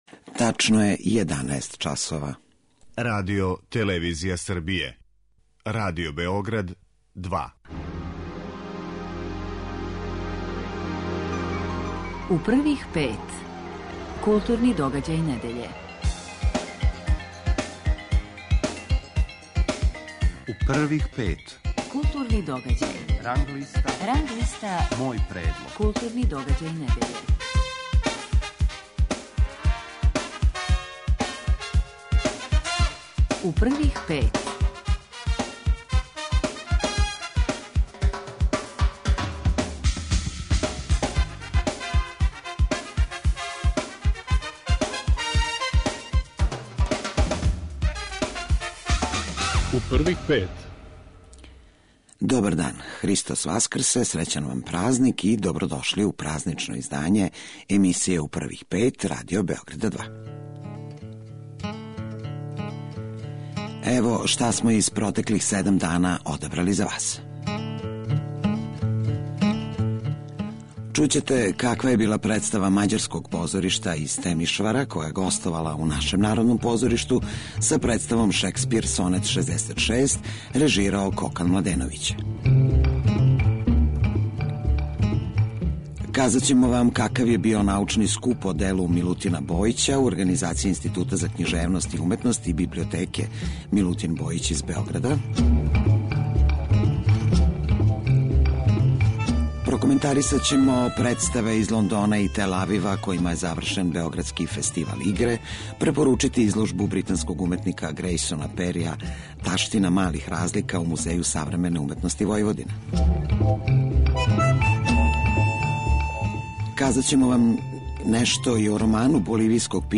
Гошћа емисије биће Хана Селимовић, глумица.